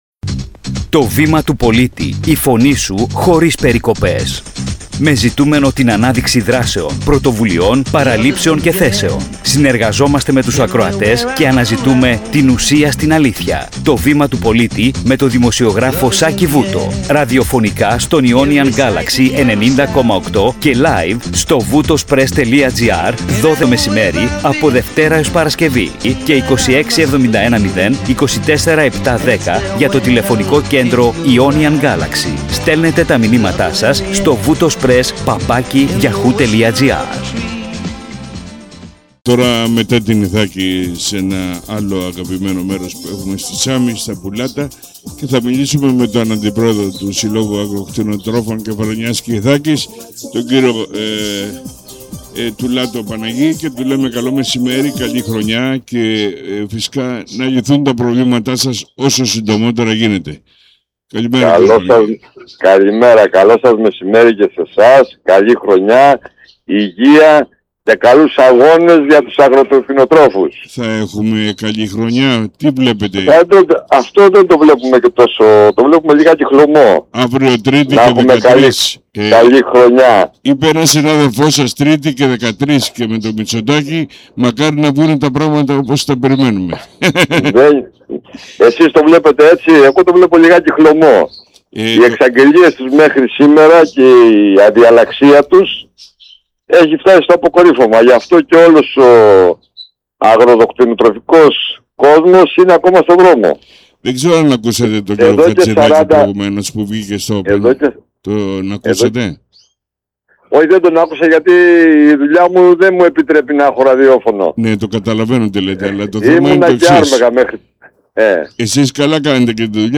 Η συζήτηση πραγματοποιήθηκε από τα Πουλάτα Σάμης και ανέδειξε τη βαθιά κρίση που βιώνουν οι κτηνοτρόφοι της Κεφαλονιά, με αιχμή τις ζωονόσους, το αυξημένο κόστος παραγωγής, τις εισαγωγές και τις ευρωπαϊκές πολιτικές.